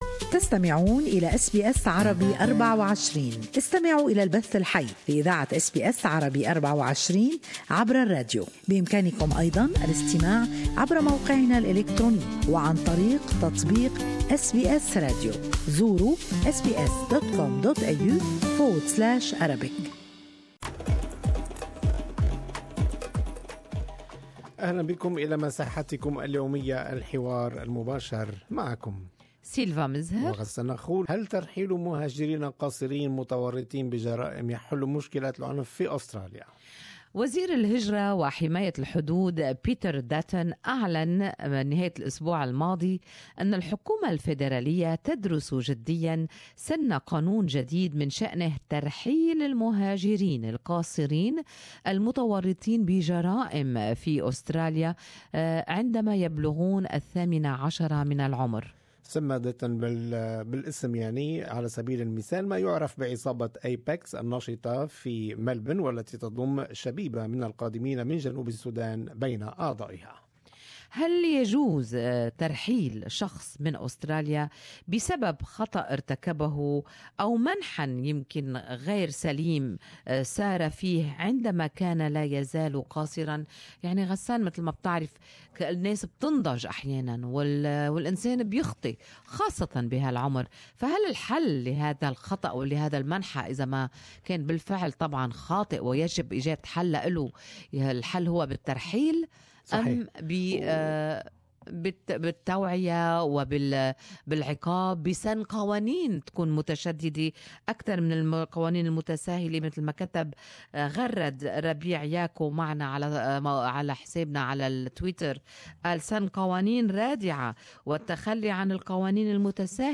The government is studying a proposal to deport minor immigrants who commit crimes in Australia. Good Morning Australia asked its listeners whether this would be a good move or not.